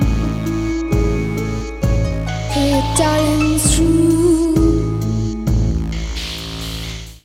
0:00 Music Box